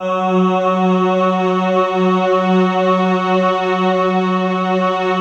Index of /90_sSampleCDs/Optical Media International - Sonic Images Library/SI1_SlowOrchPad/SI1_SlowPad